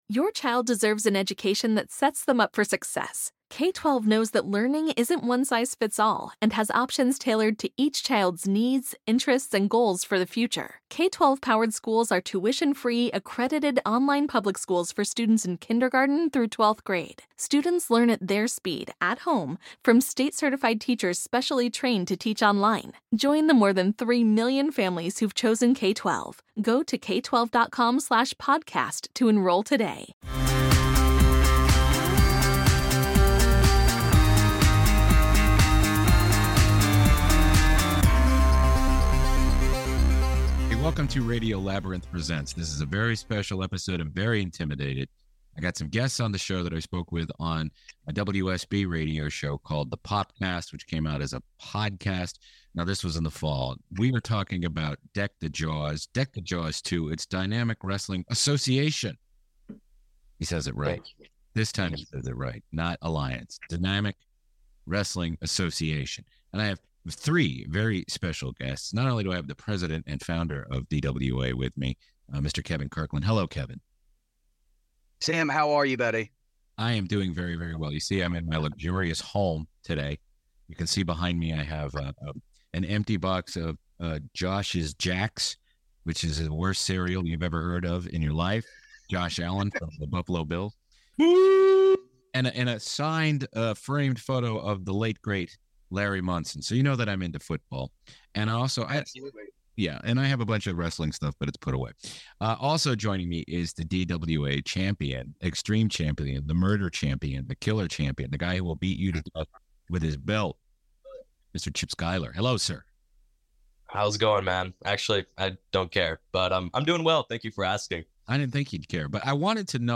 Dive into the world of intense wrestling excitement with our latest interview on Radio Labyrinth Presents Interviews!